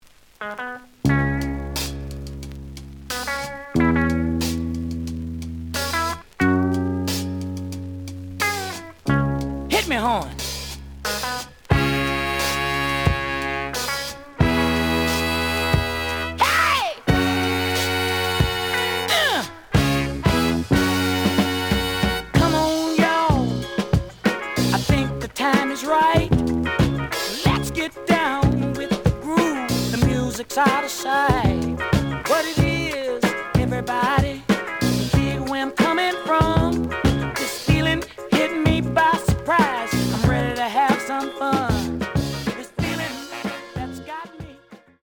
The audio sample is recorded from the actual item.
●Genre: Funk, 70's Funk
Slight click noise on both sides due to a bubble.)